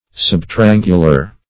Search Result for " subtriangular" : The Collaborative International Dictionary of English v.0.48: Subtriangular \Sub`tri*an"gu*lar\, a. Nearly, but not perfectly, triangular.
subtriangular.mp3